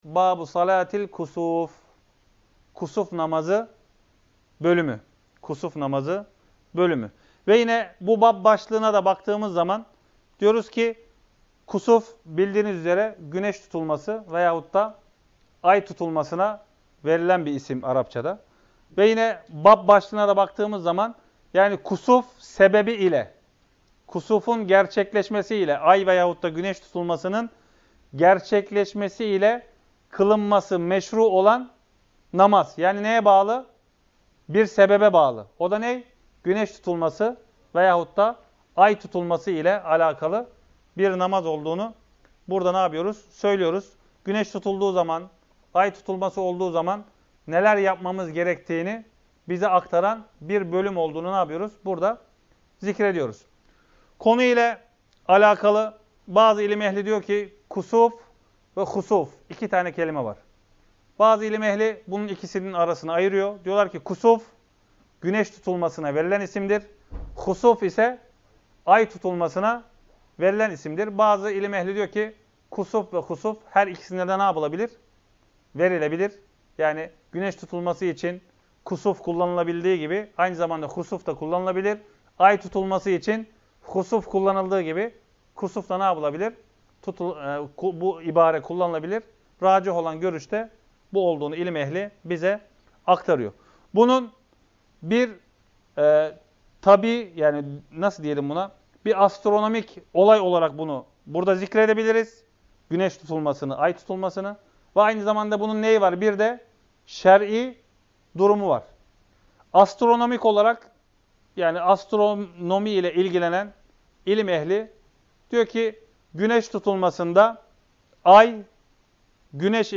1. Ders - 15- Küsuf Namazı Bölümü